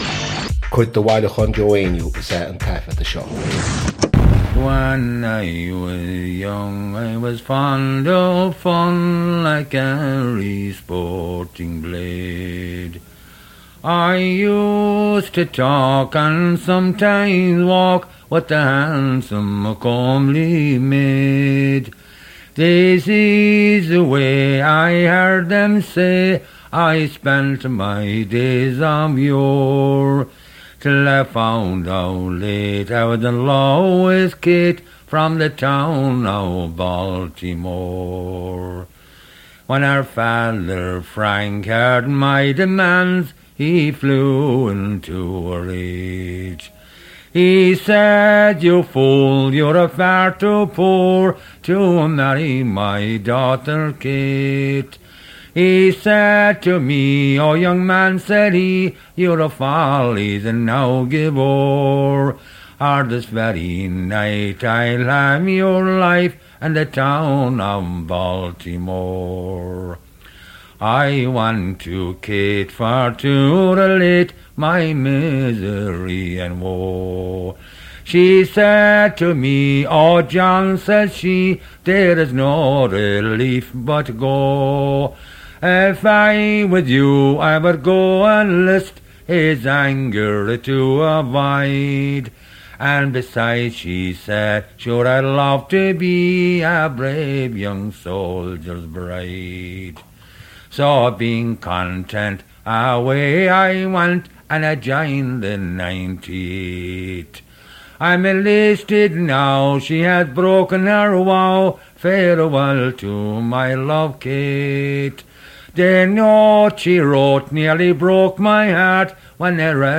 • Catagóir (Category): song.
• Ainm an té a thug (Name of Informant): Joe Heaney.
• Suíomh an taifeadta (Recording Location): New York, United States of America.
• Ocáid an taifeadta (Recording Occasion): workshop.